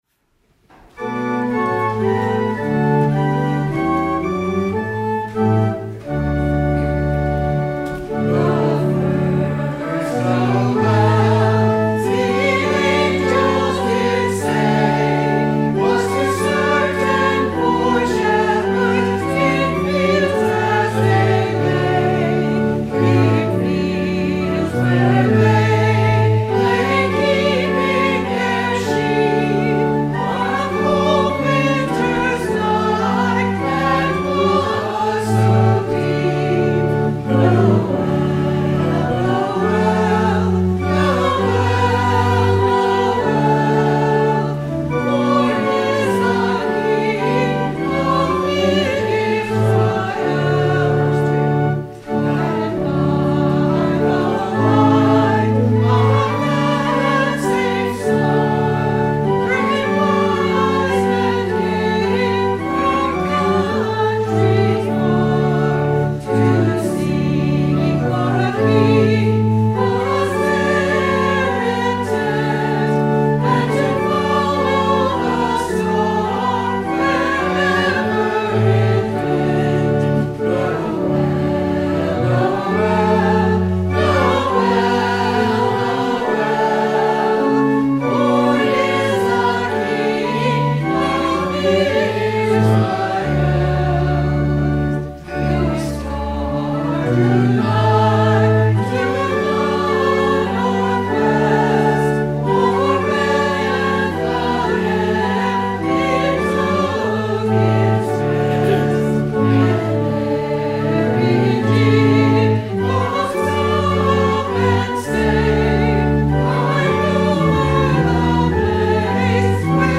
Sung by the Church and Choir.